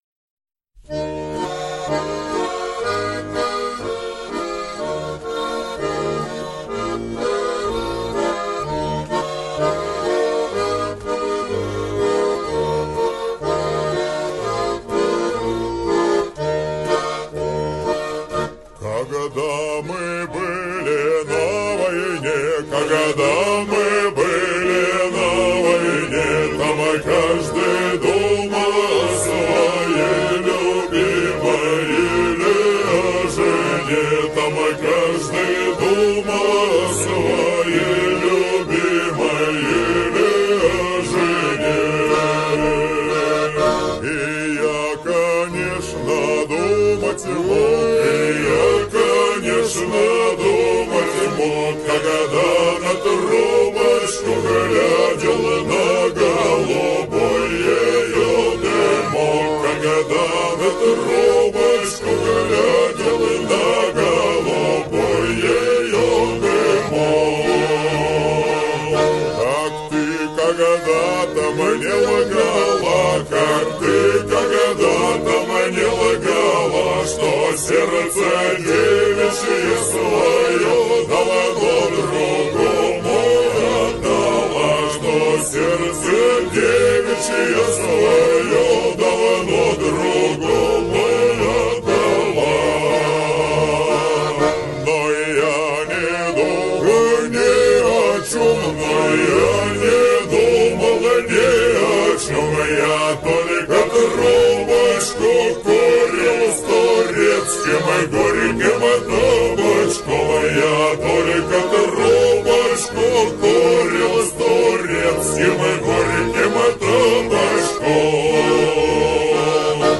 Два вопроса про казачьи песни
Когда мы были на войне это не "Братина", "Казачий Круг" трек выловил не с музыкального иностранного сайта, но запись студийная, ... будем искать, версия понравилась.